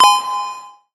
Fx [Ethereal].wav